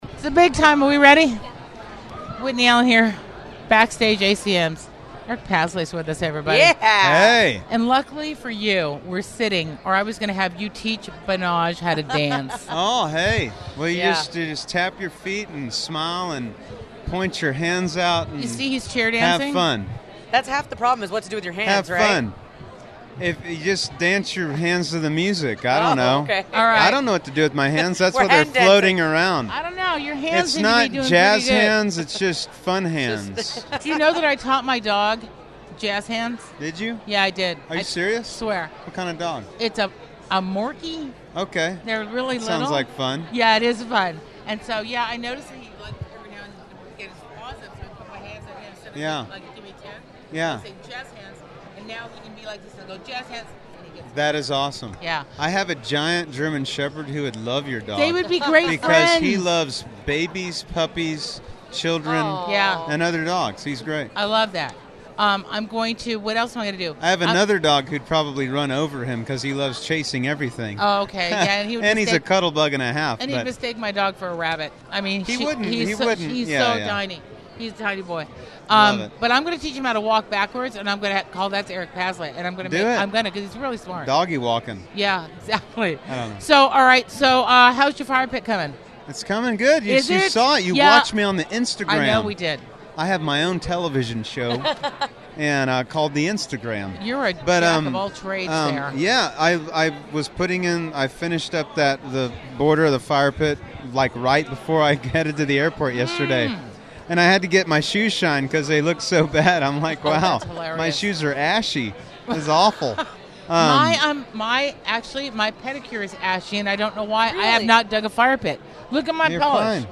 Eric Paslay Interview At The 2018 ACMs!